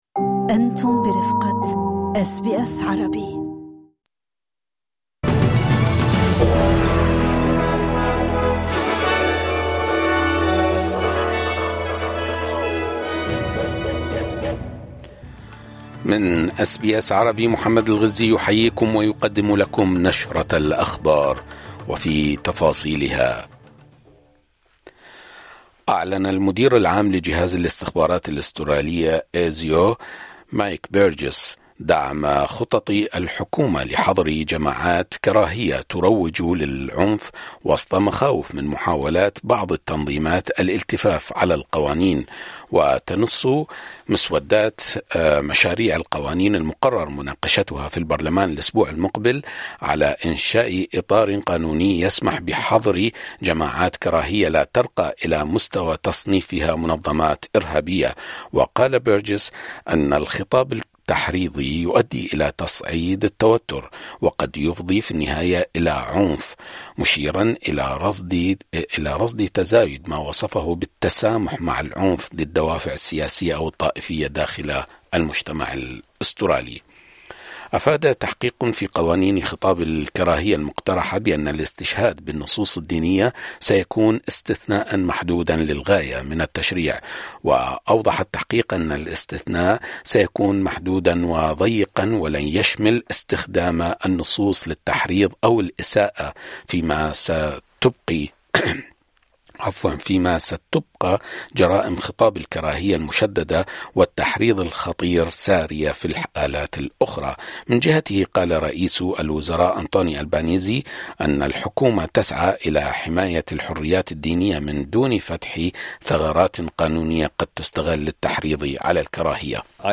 نشرة أخبار الظهيرة 14/01/2025